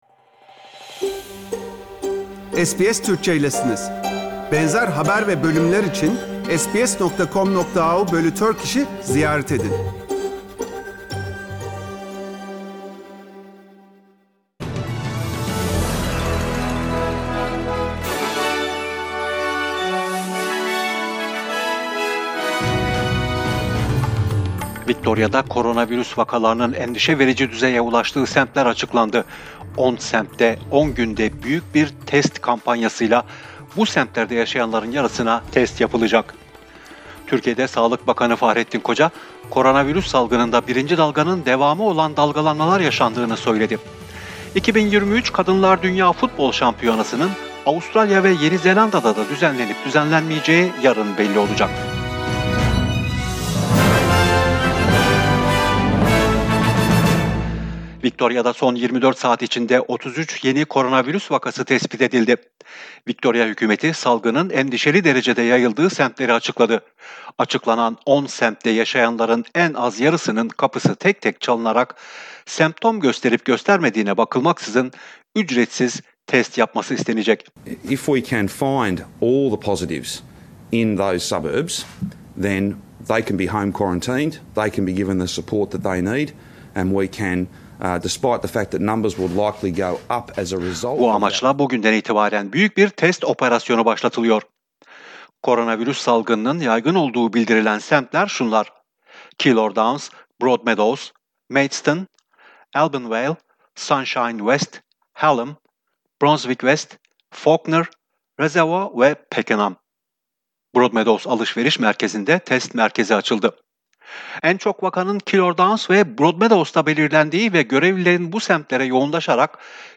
SBS Türkçe Haberler 25 Haziran